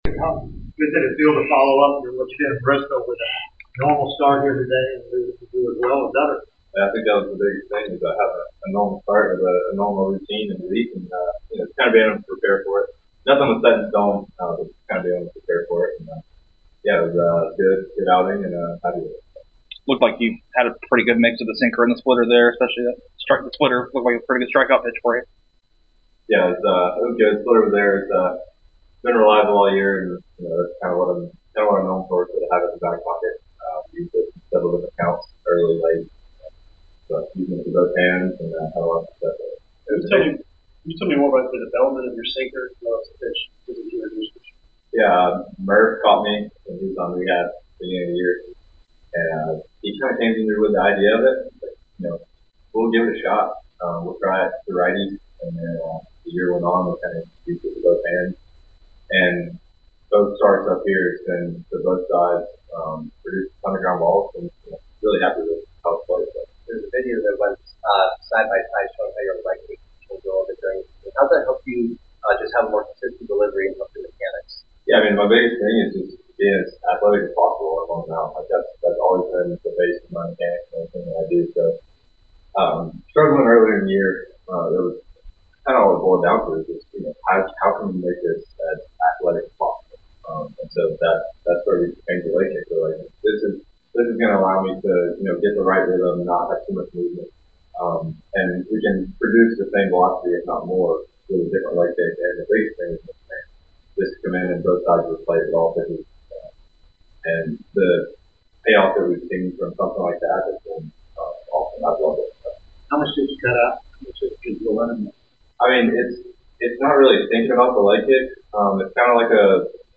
Atlanta Braves Pitcher Hurston Waldrep Postgame Interview after defeating the Miami Marlins at Truist Park.